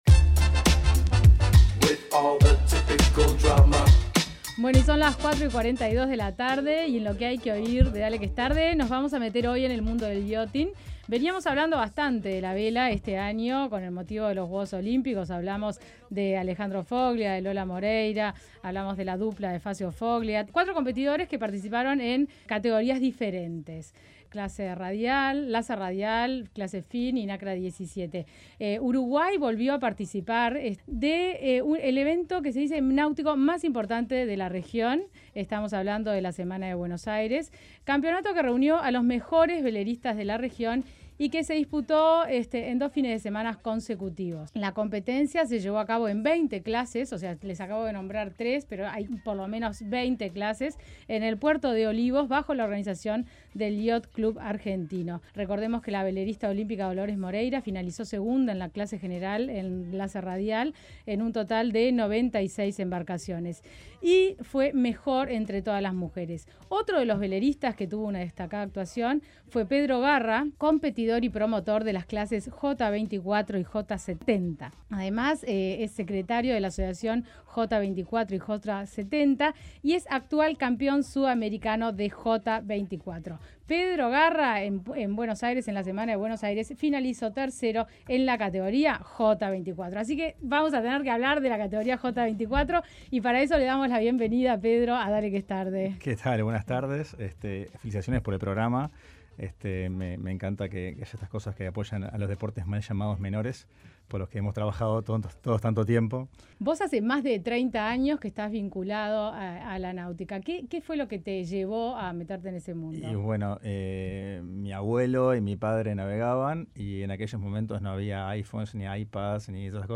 Durante la entrevista en Dale que es Tarde tuvimos la oportunidad de hablar sobre los inicios de su carrera, los principales eventos deportivos en la región y a nivel mundial en los que participó, y sobre su próxima deportiva que será el Sudamericano J24 que se llevará a cabo en Punta del Este, entre el 6 y el 11 de diciembre.